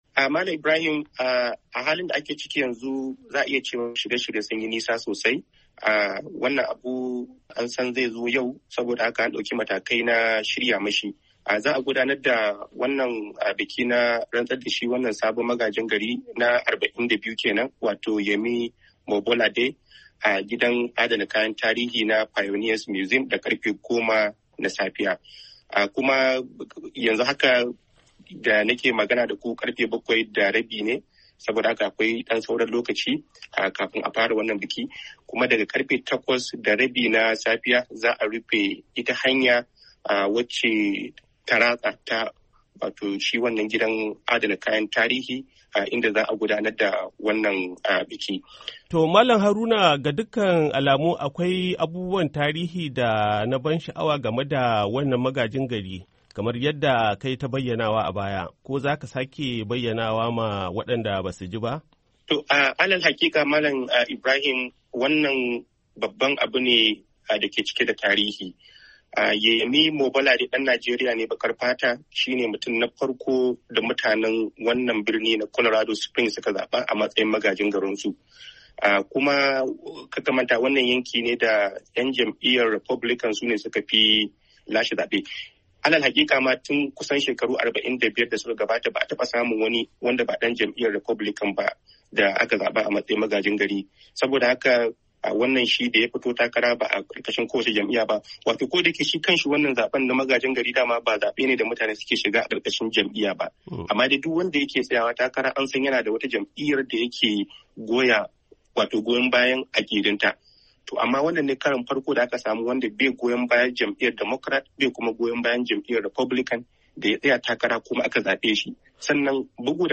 Hira Da Yemi Mobolade Sabon Magajin Garin Birnin Colorado Springs Da Ke Jihar Colorado